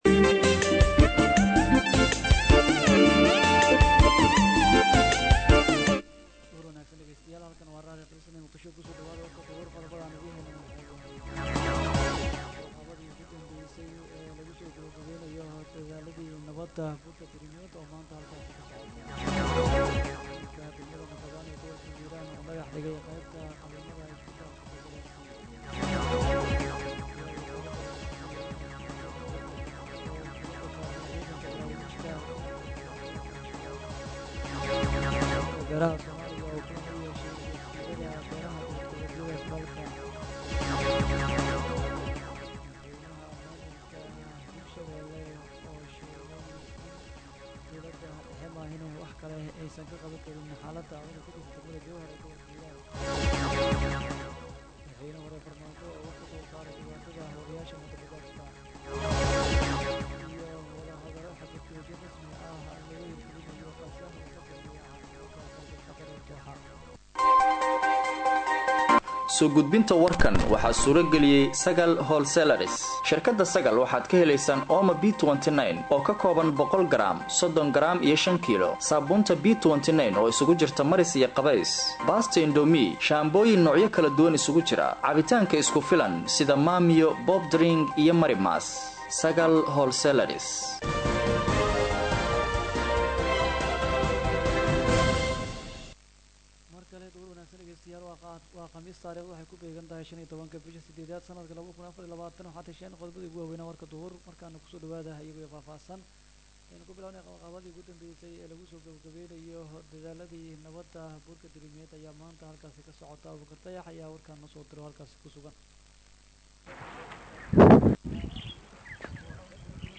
Dhageyso:-Warka Duhurnimo Ee Radio Dalsan 15/08/2024